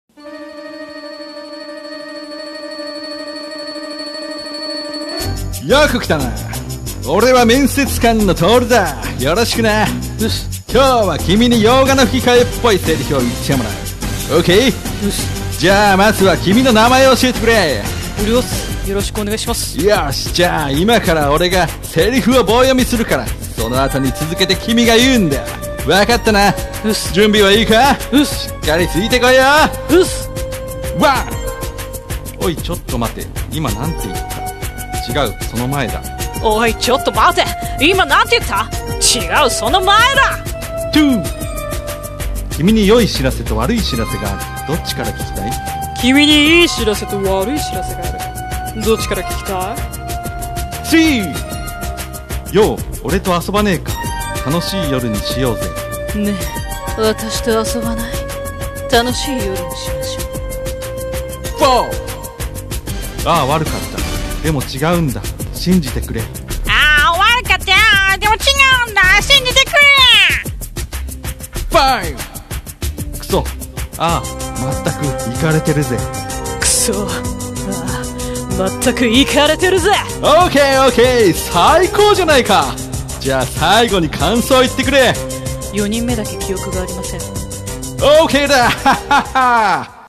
【声面接】洋画の吹き替え声面接